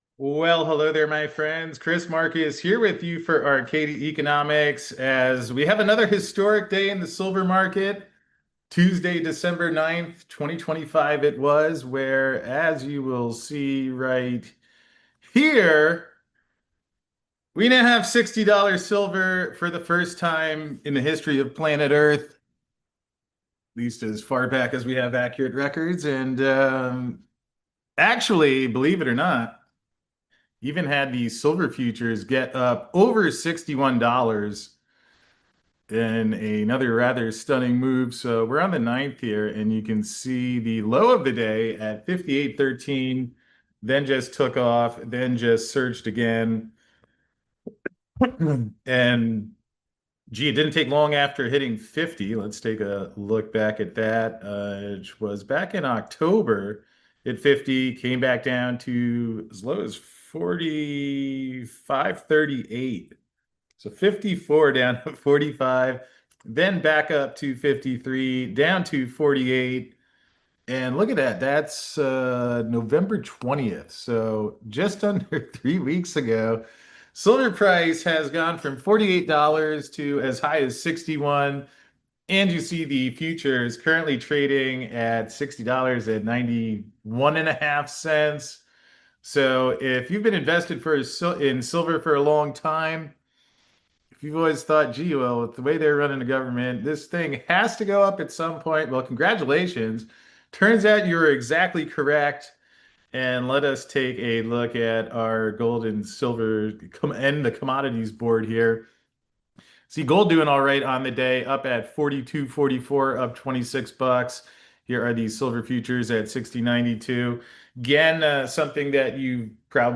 Beschreibung vor 1 Monat Silver Price Breaks $60 For First Time In History!! (And Then Breaks $61 Too) Congratulations on seeing history being made, as the silver price has just broken the $60 level (and then the futures even broke through $61 for good measure). It's another stunning day in the precious metals markets, and to find out more about what's happening, join us at noon Eastern for this special live broadcast!